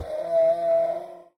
mob / horse / donkey / death.ogg